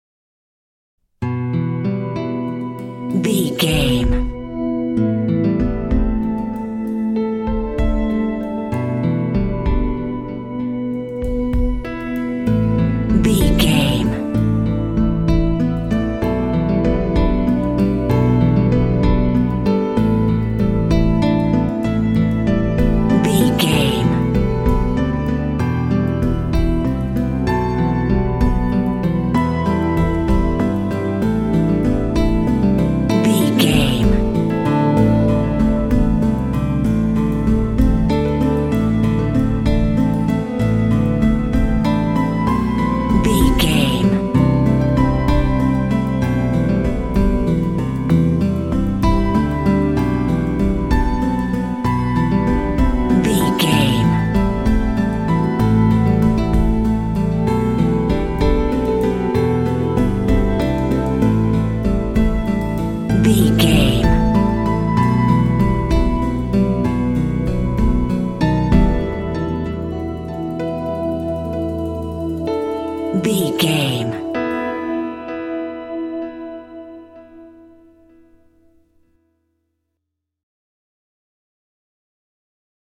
Aeolian/Minor
sentimental
piano
synthesiser
acoustic guitar
drums
ambient
film score